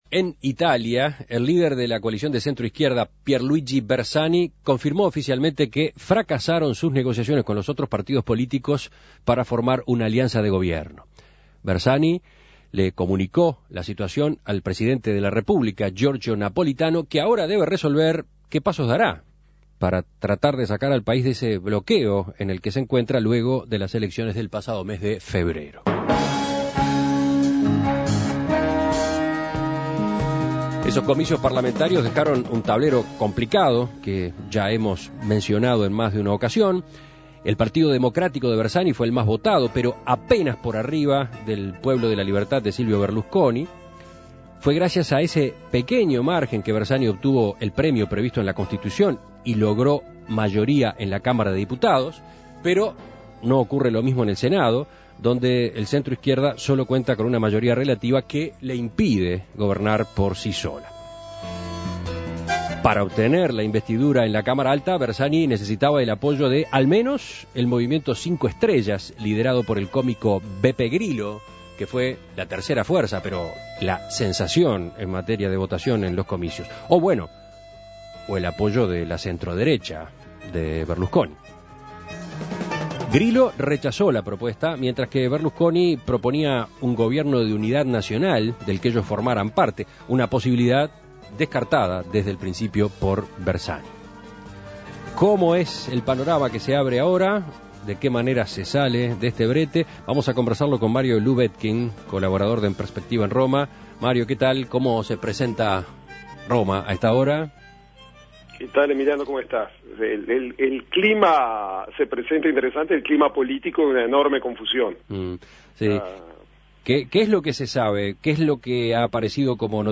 Contacto con Mario Lubetkin, colaborador de En Perspectiva en Roma.